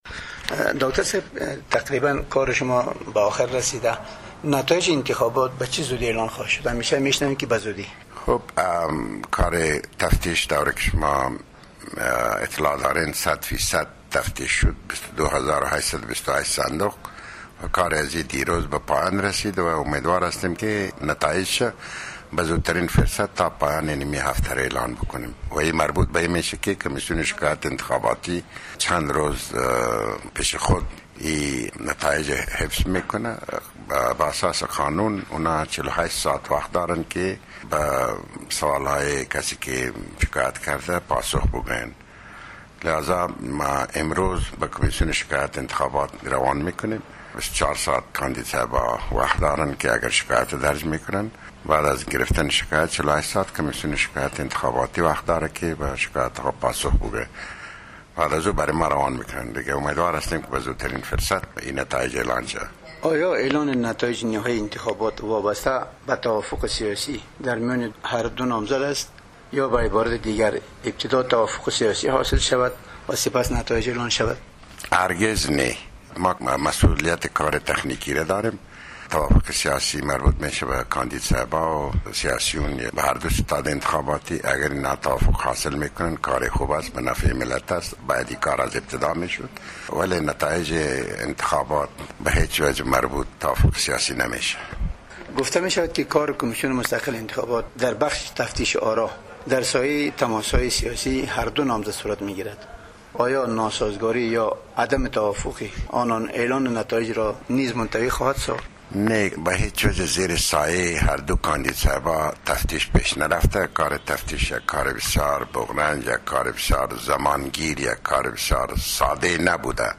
مصاحبه ها
Nooristani Interview